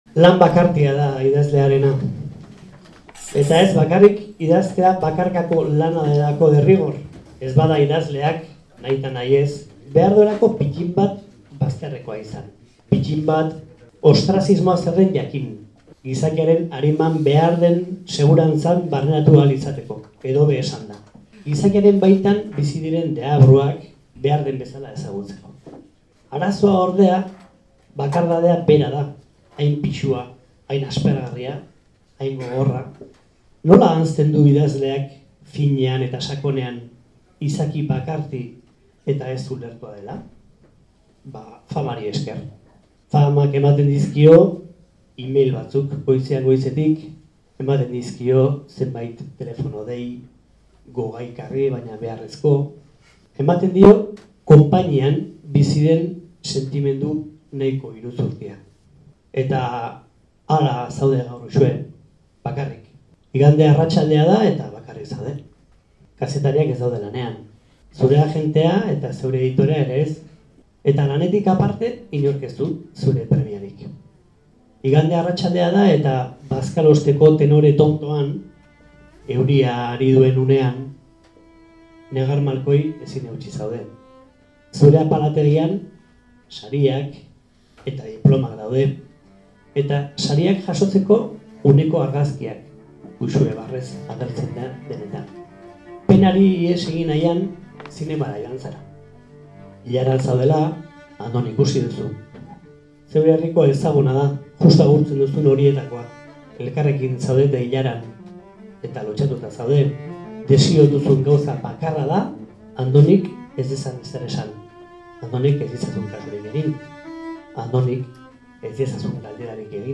Getariako Elkano jatetxean afari giro ederrean Andoni Egaña eta Uxue Alberdi bertsotan